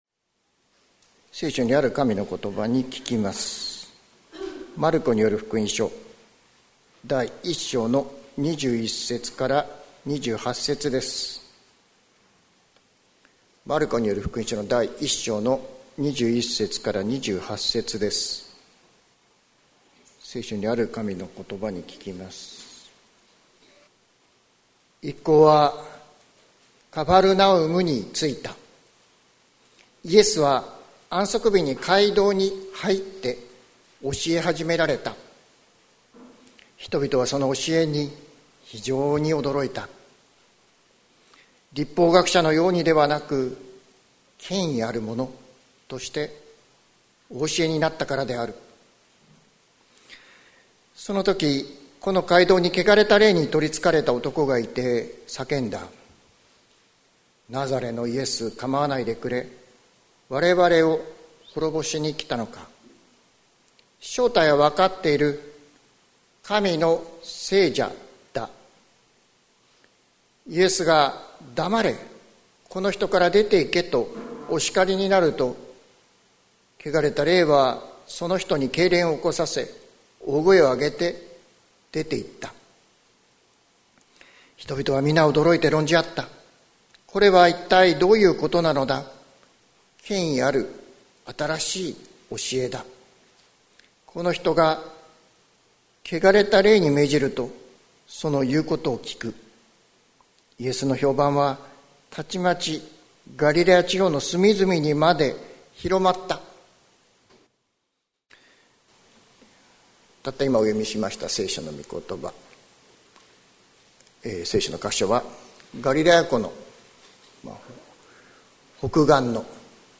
2025年04月27日朝の礼拝「コトバの力に生かされて」関キリスト教会
説教アーカイブ。